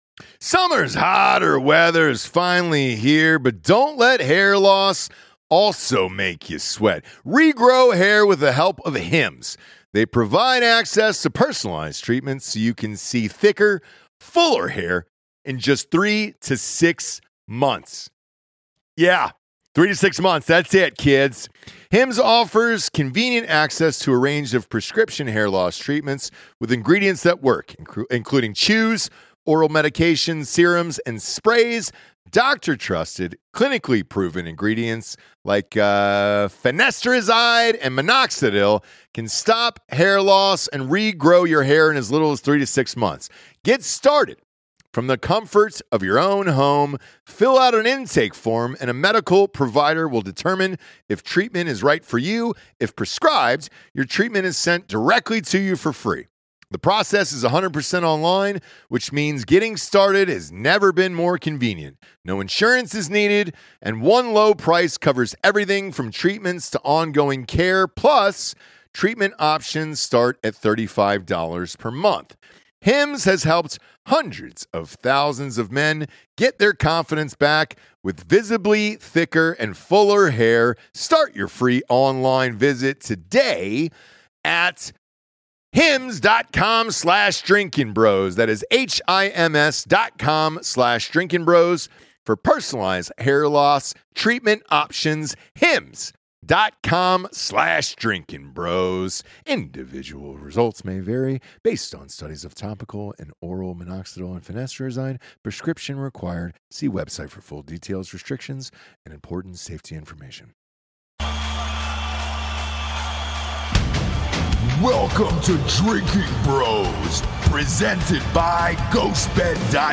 Episode 821 - Special Guest Theoretical Physicist Avi Loeb